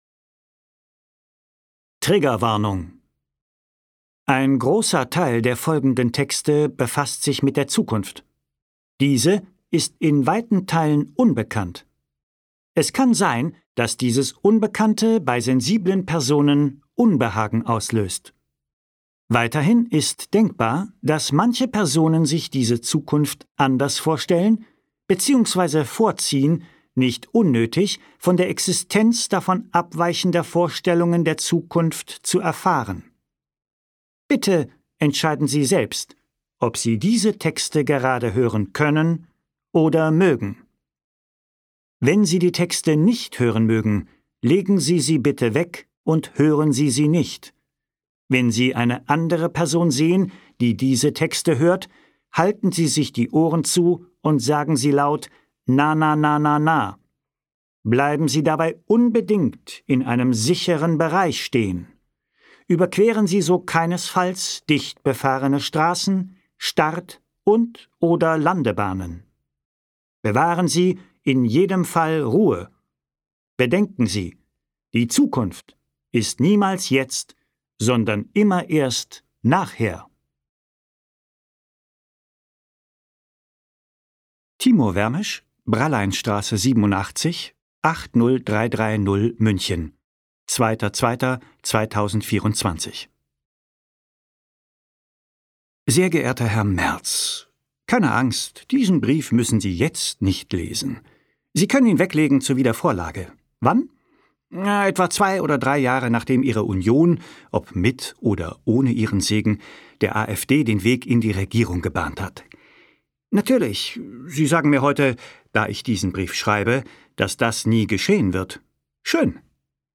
Briefe von morgen, die wir gern gestern schon gelesen hätten Timur Vermes (Autor) Michael Kessler (Sprecher) Audio-CD 2025 | 1.